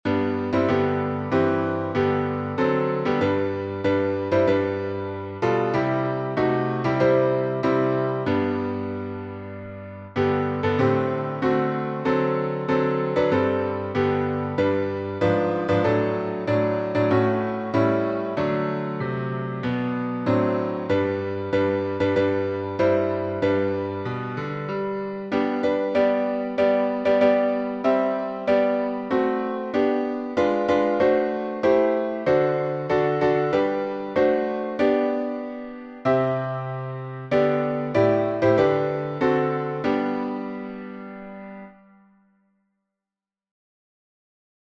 Words by Jesse B. Pounds (1861-1921)Tune: WAY OF THE CROSS by Charles Hutchinson Gabriel (1856-1932)Key signature: G major (1 sharp)Time signature: 4/4Meter: IrregularPublic Domain1.